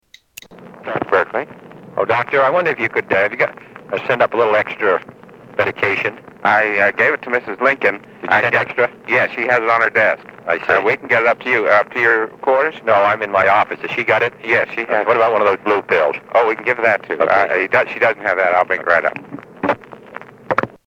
Tags: White House tapes Presidents Secret recordings Nixon tapes White house